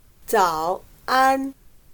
早安/Zǎo ān/Buenos dias